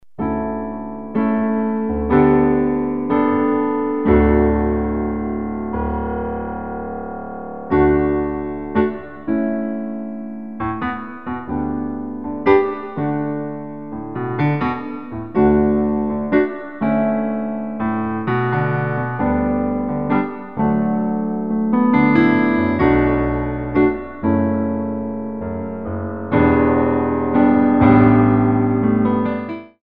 45 selections (67 minutes) of Original Piano Music